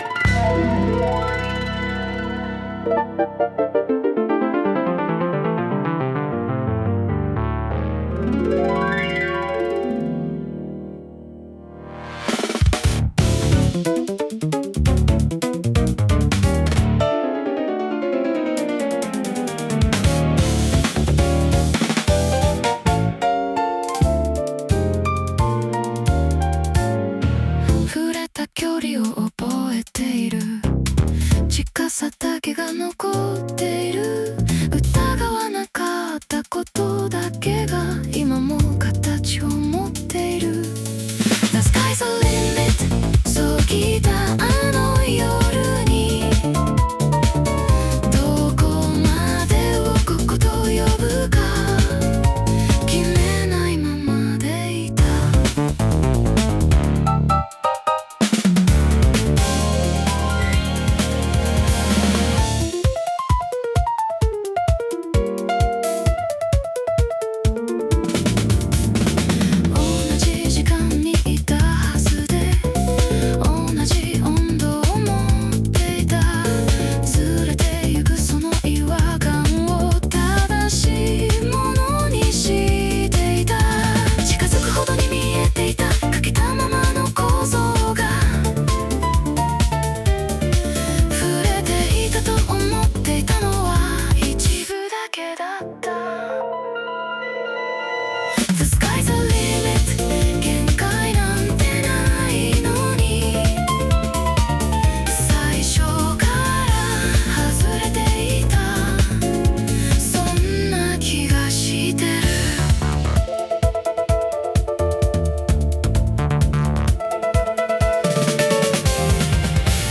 女性ボーカル